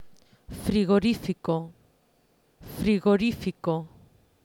Escribe junto a las que no lo necesiten la palabra NO. Vas a escuchar cada palabra dos veces.